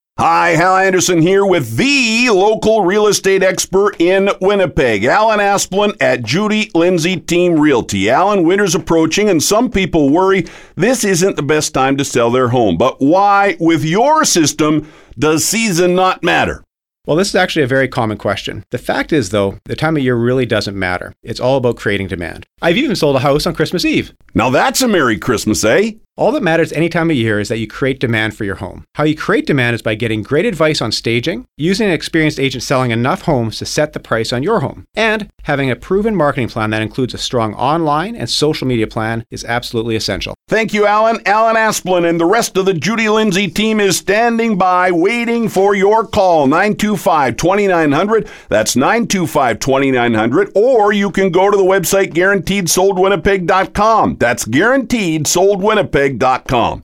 Interview 3